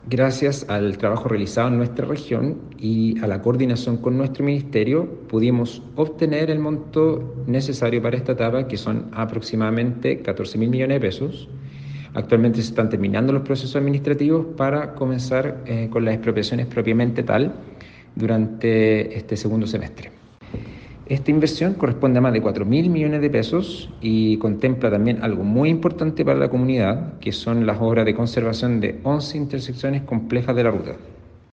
Escucha las declaraciones del Seremi MOP Javier Sandoval a continuación.